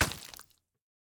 Minecraft Version Minecraft Version snapshot Latest Release | Latest Snapshot snapshot / assets / minecraft / sounds / mob / drowned / step2.ogg Compare With Compare With Latest Release | Latest Snapshot
step2.ogg